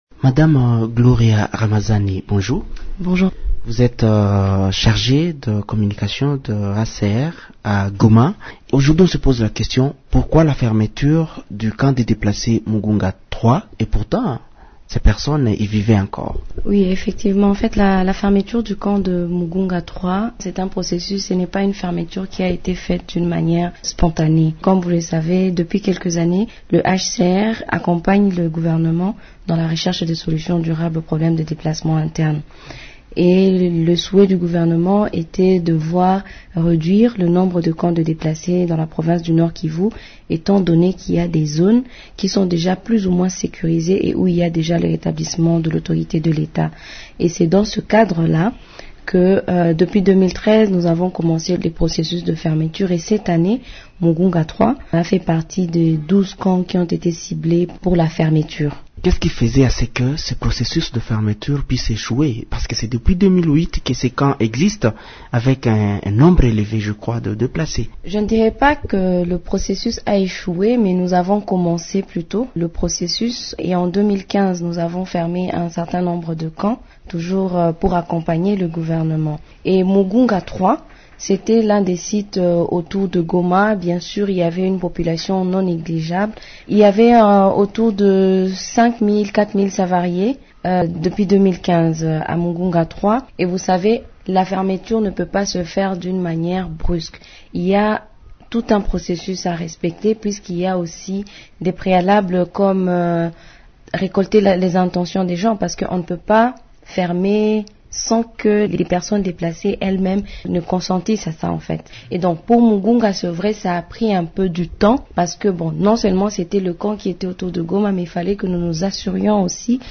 Elle parle de la fermeture de ce camp dans cette entrevue